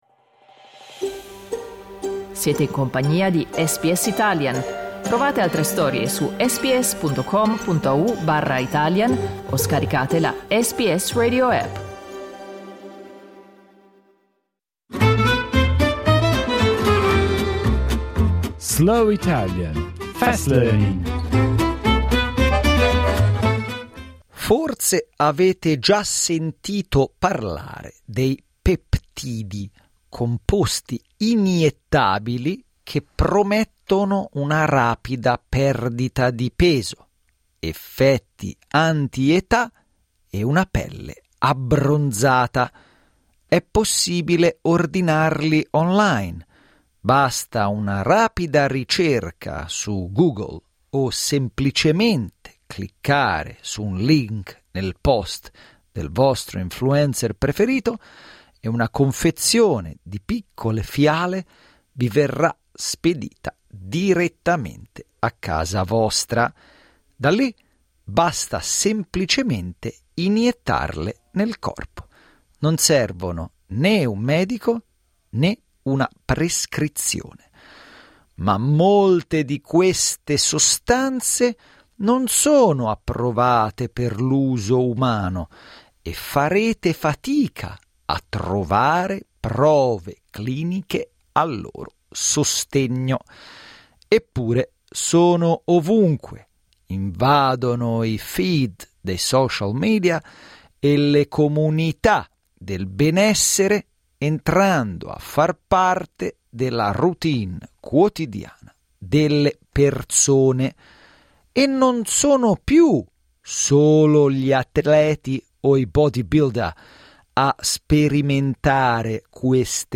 Slow Italian, Fast Learning